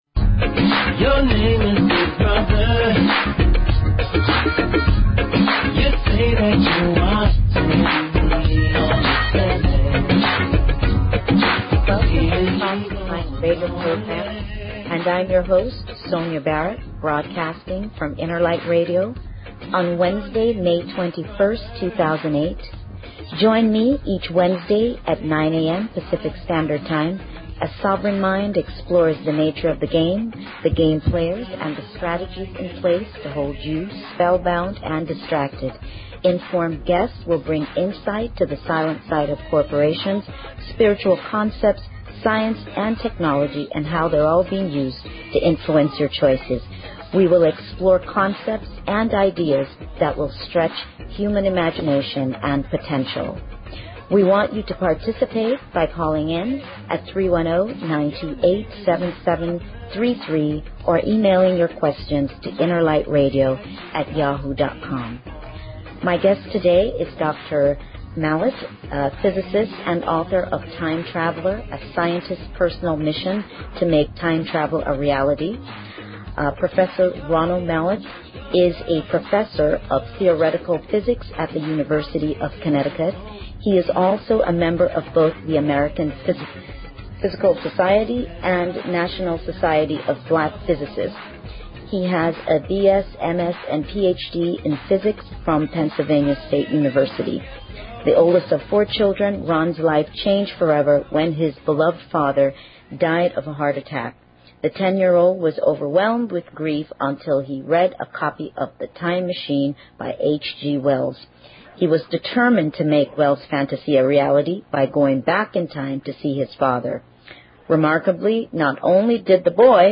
Talk Show Episode, Audio Podcast, Sovereign_Mind_Radio and Courtesy of BBS Radio on , show guests , about , categorized as